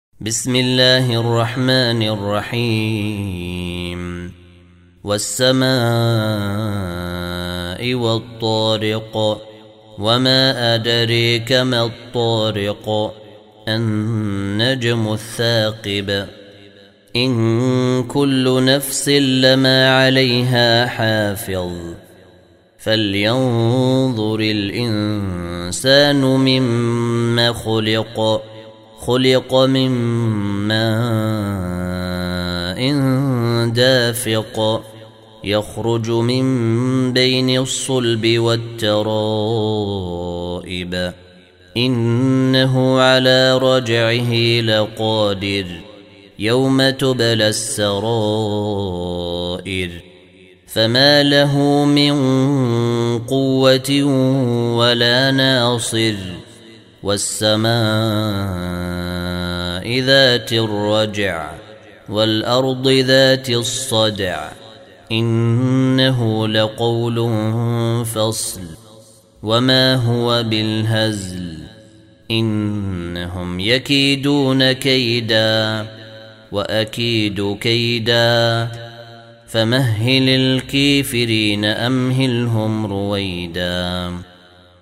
Surah Repeating تكرار السورة Download Surah حمّل السورة Reciting Murattalah Audio for 86. Surah At-T�riq سورة الطارق N.B *Surah Includes Al-Basmalah Reciters Sequents تتابع التلاوات Reciters Repeats تكرار التلاوات